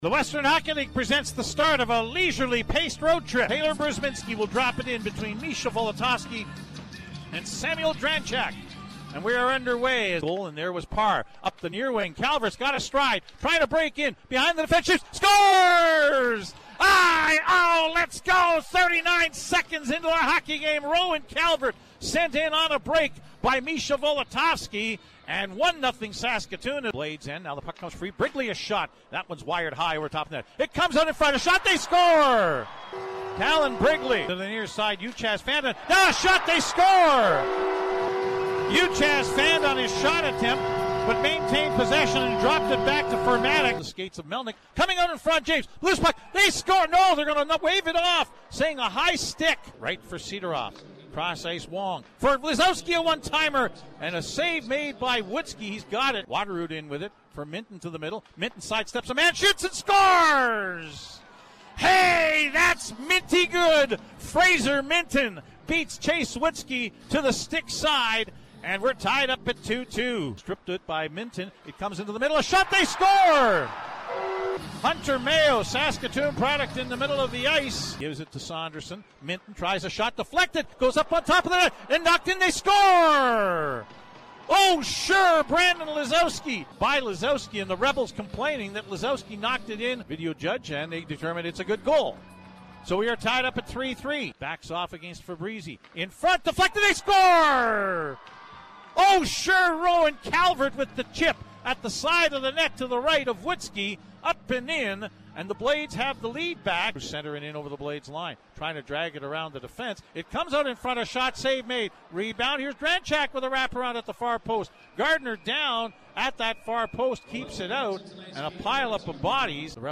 audio highlights from CJWW’s broadcast